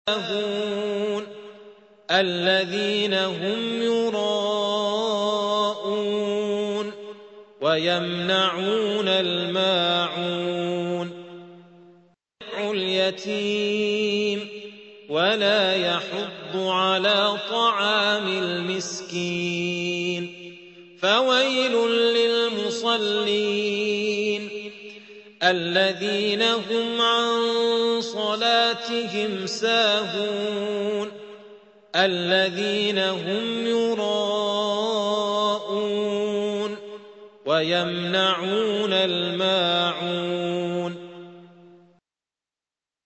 سورة الماعون / القارئ